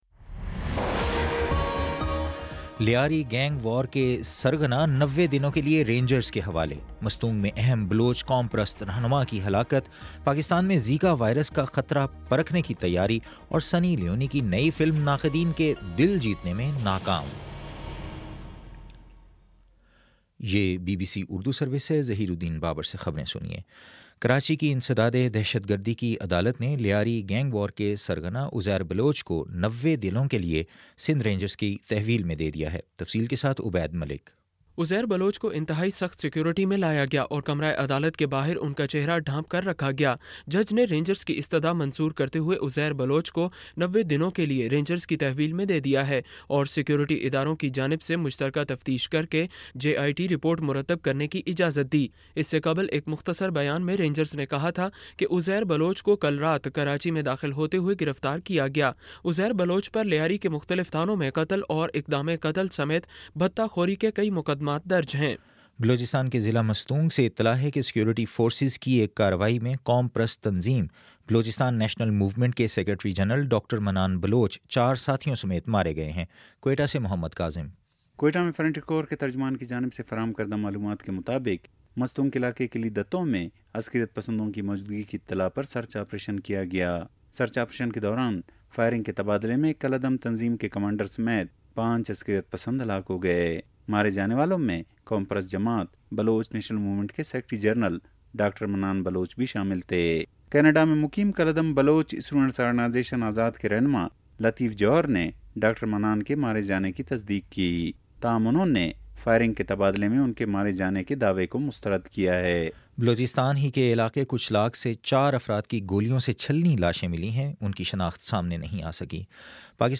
جنوری 30 : شام چھ بجے کا نیوز بُلیٹن